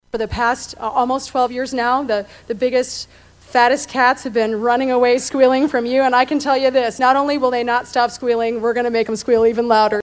ERNST, WHO ANNOUNCED SHE WOULD NOT RUN FOR RE-ELECTION FOUR MONTHS AGO, JOINED HINSON THIS PAST WEEKEND AT A CAMPAIGN EVENT IN ADEL.